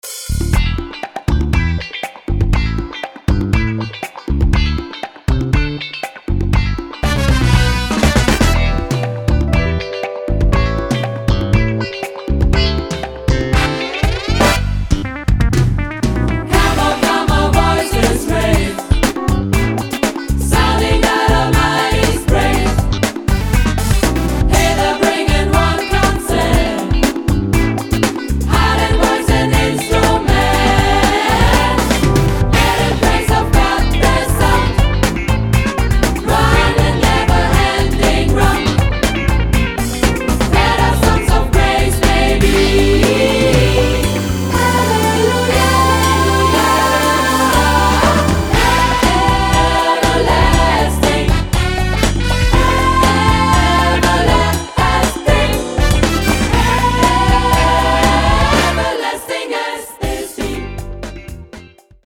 SAB / SSA + Piano